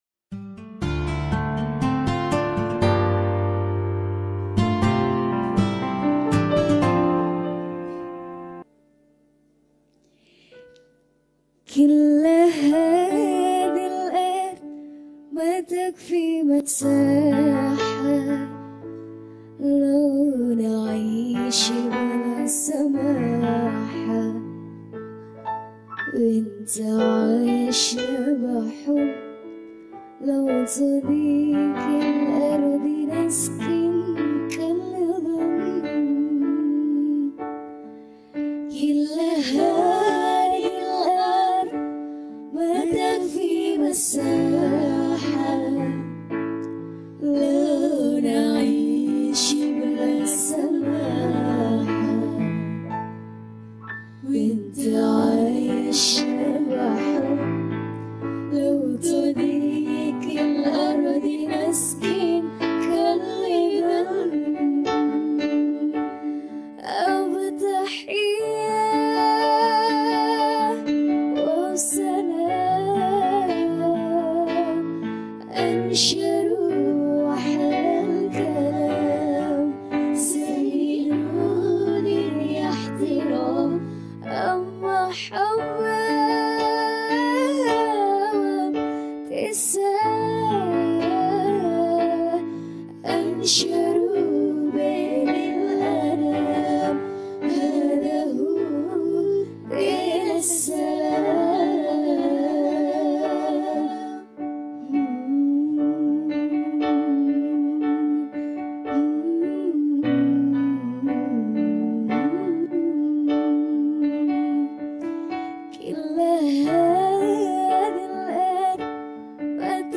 New Qasidah